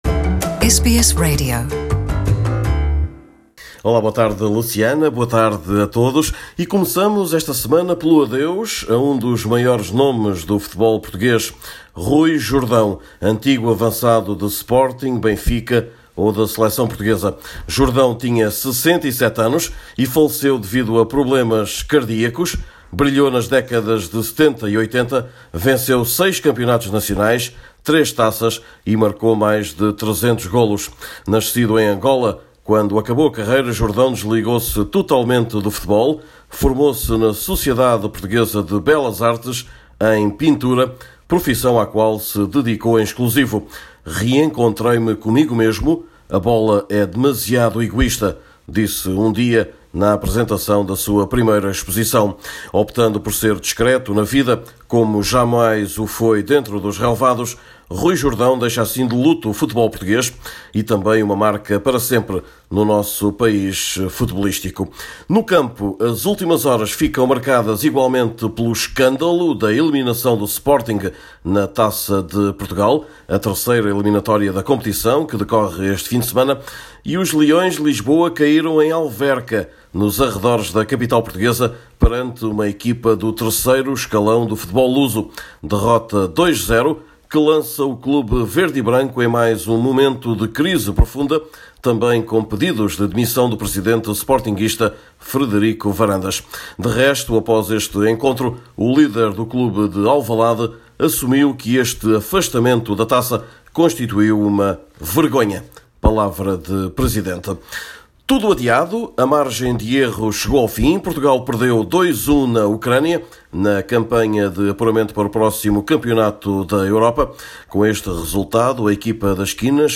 Neste boletim semanal falamos ainda da seleção lusa rumo ao Euro-2020, do escândalo da eliminação do Sporting da Taça, de Jorge Jesus no Brasil ou de alguns técnicos portugueses que são selecionadores “lá fora”.